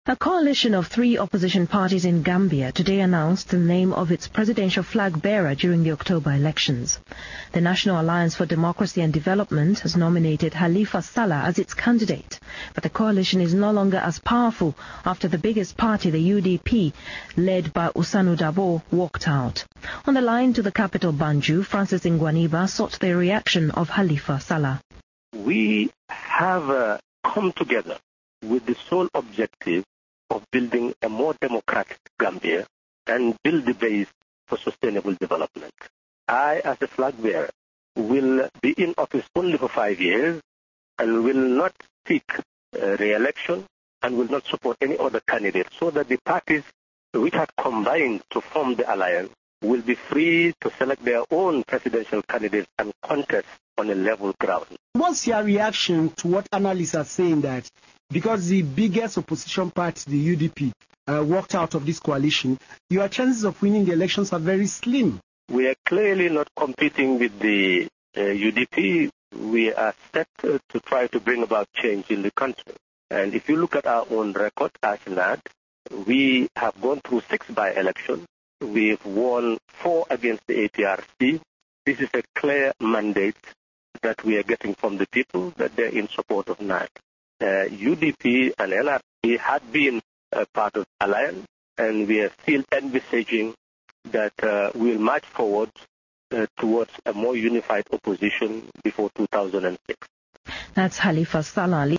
I have just been listening to the BBC Focus on Africa interview with Hon. Halifa Sallah.
What a joyous moment to hear from his own mouth that he is now the NADD presidential candidate.